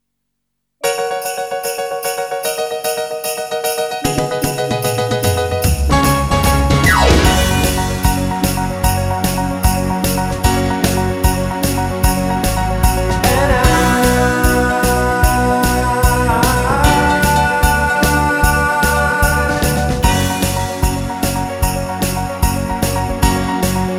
One Semitone Down Pop (1990s) 3:49 Buy £1.50